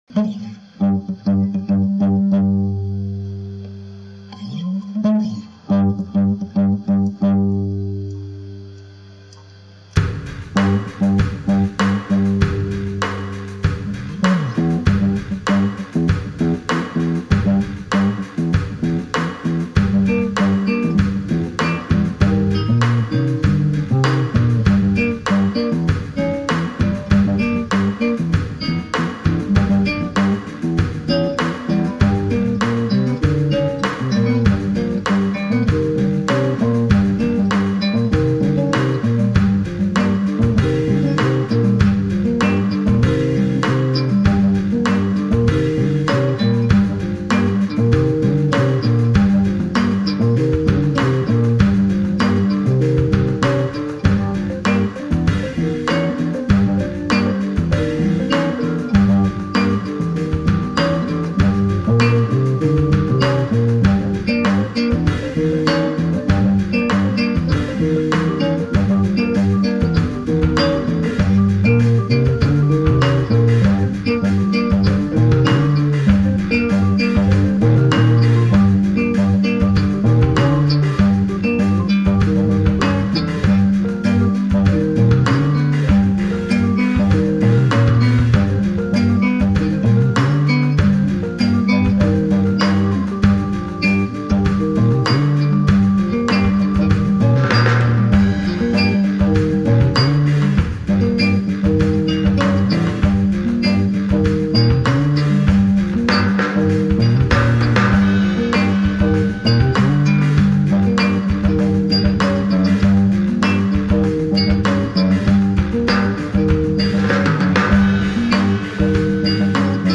dance/electronic
World beats
NuJazz